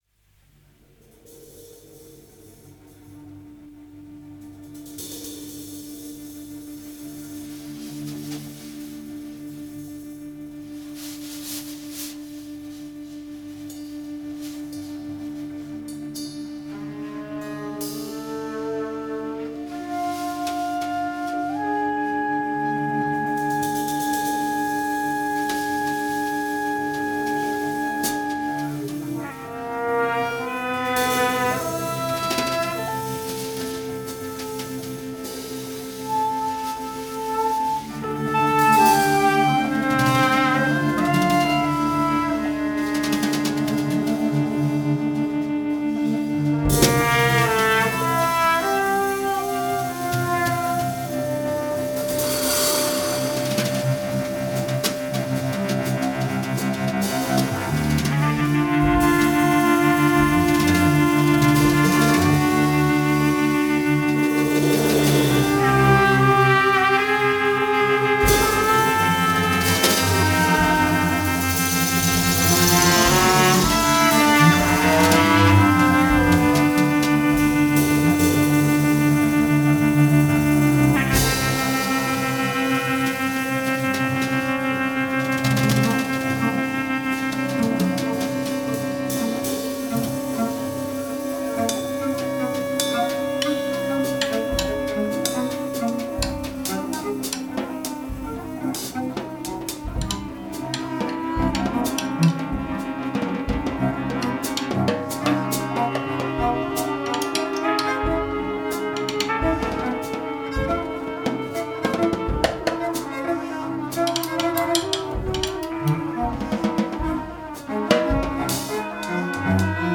trumpet, trombone and voice
clarinet and voice
electric guitar and voice
cello and voice
drums and voice